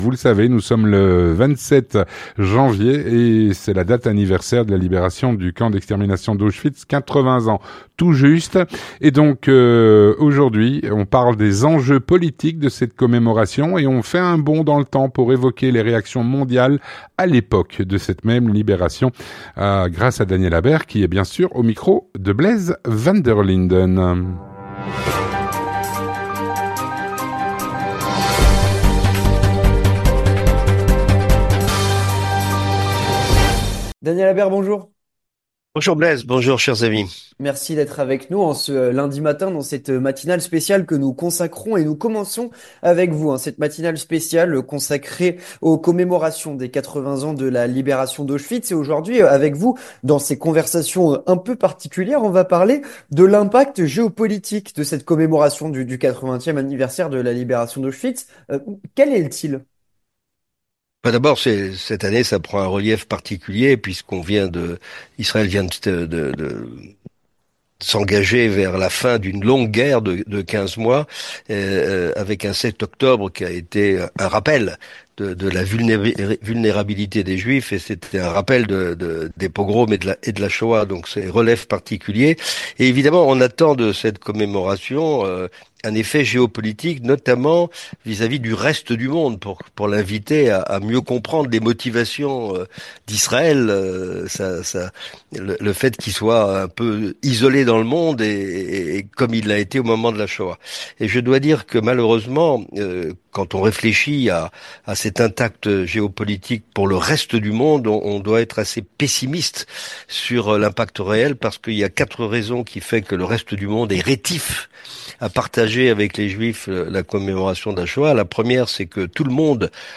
géopolitologue et chroniqueur sur Radio Judaïca